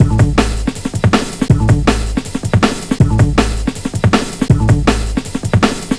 Index of /90_sSampleCDs/Zero-G - Total Drum Bass/Drumloops - 1/track 16 (160bpm)